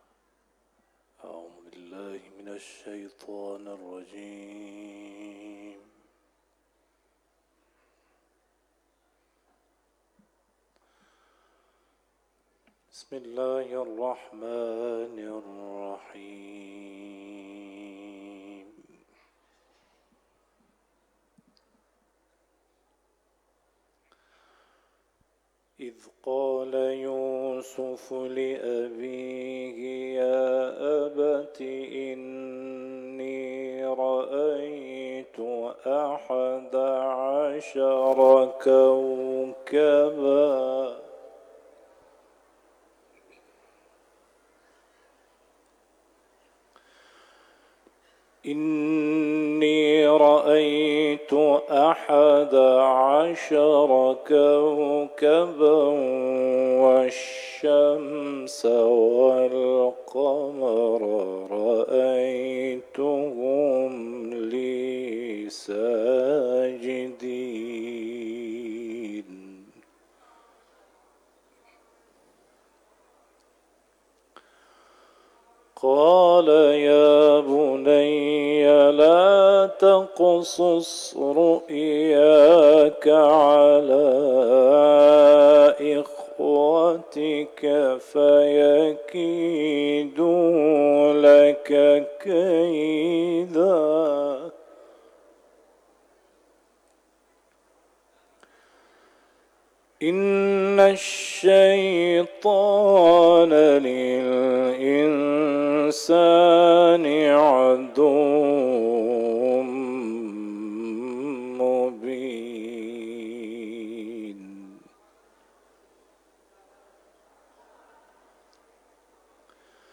حرم مطهر رضوی ، سوره یوسف ، تلاوت قرآن